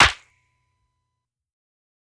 Snares
SNARE_SMITHSO2.wav